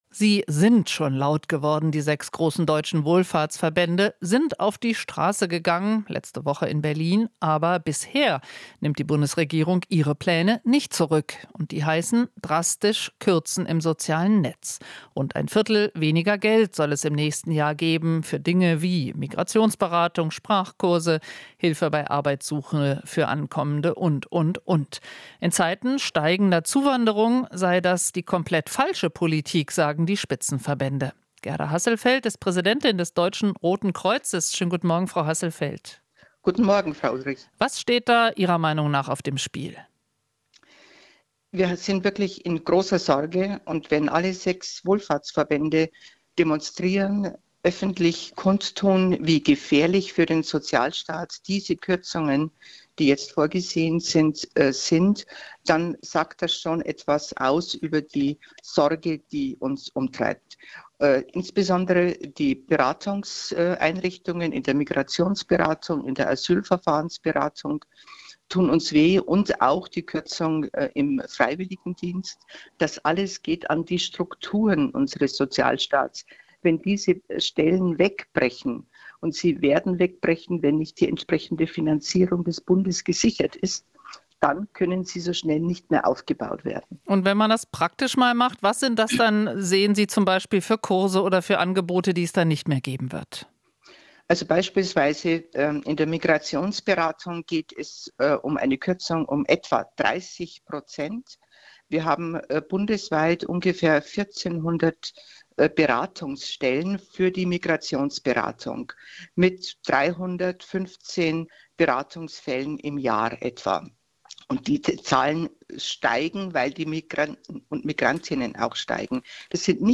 Interview - Rotes Kreuz: Kürzung durch Bund geht an "Struktur unseres Sozialstaats"